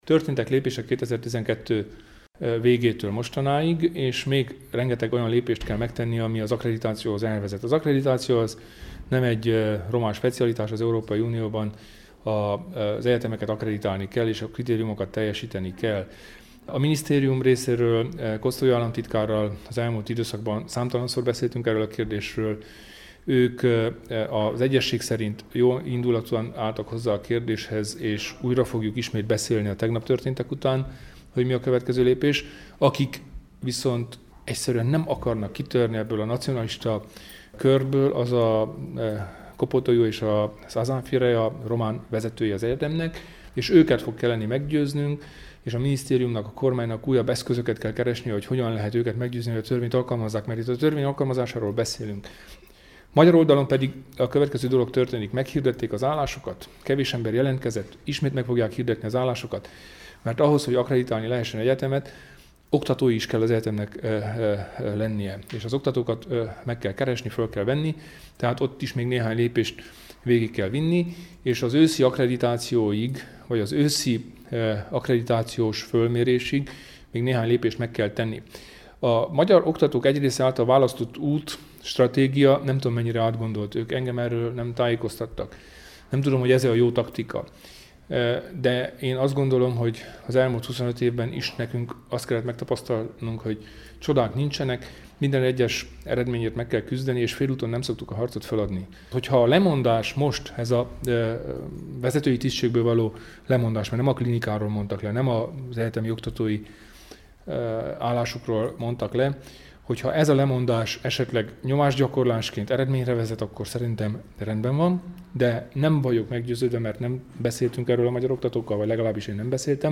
Kelemen Hunor miniszterelnök-helyettes, kulturális miniszter, az RMDSZ szövetségi elnöke pénteken Aradon fejtette ki véleményét a MOGYE-ügyről az újságírók előtt.